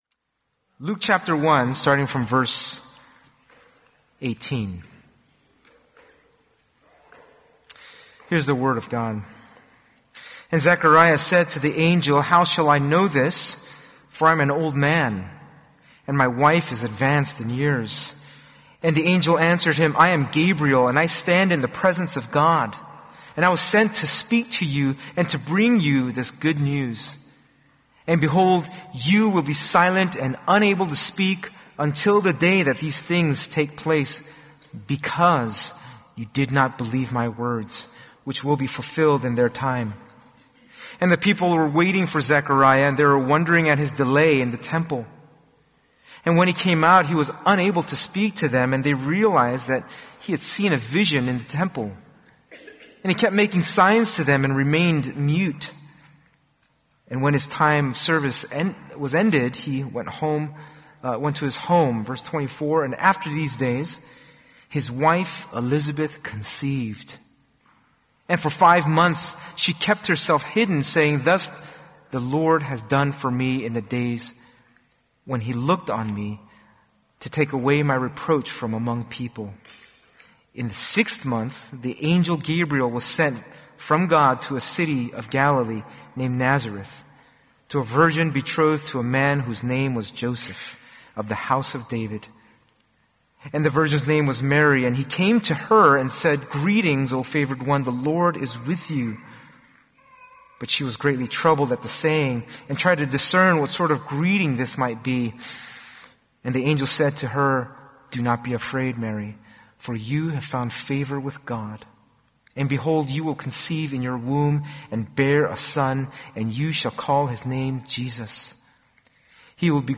2026 (Sunday Service)Bible Text